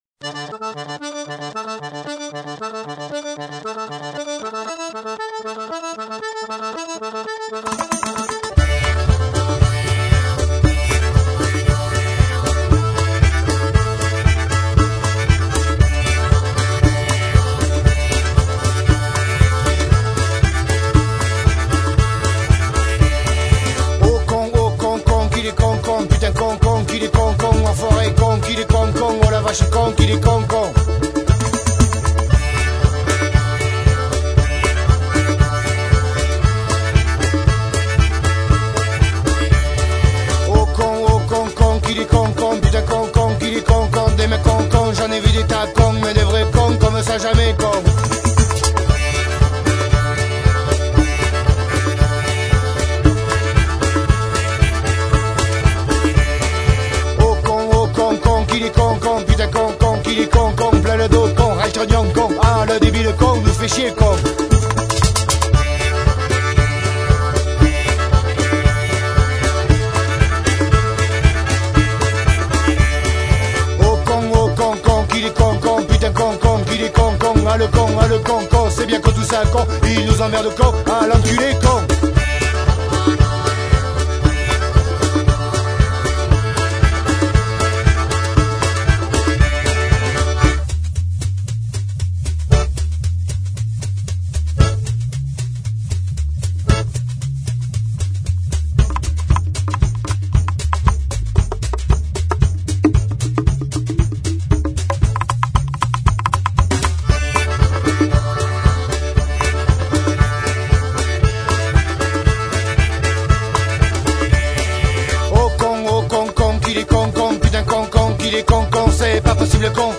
rock cold wave et psychédélique des années 80